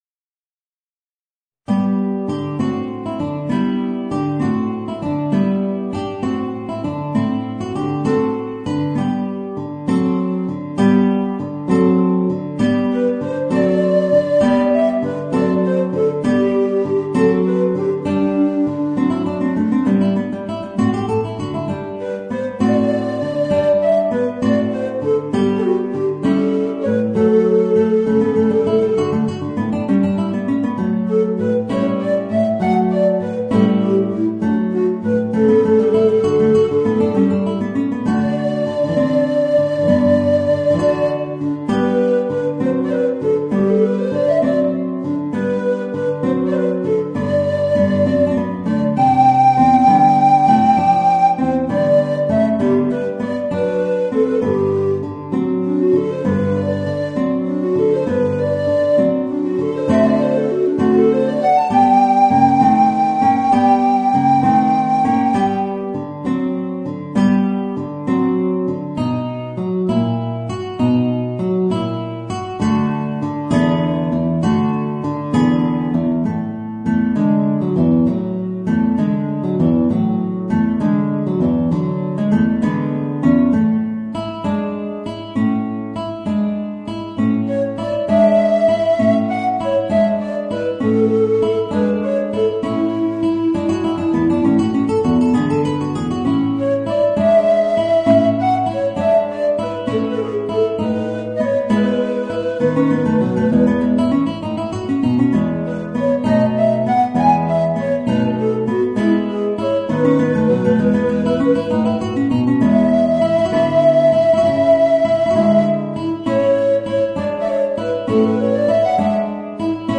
Voicing: Tenor Recorder and Guitar